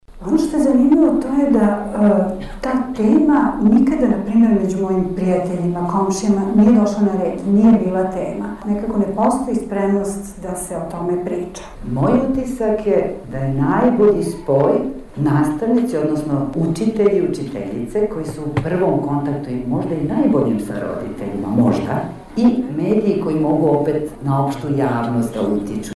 Evo kako razmišljaju majke .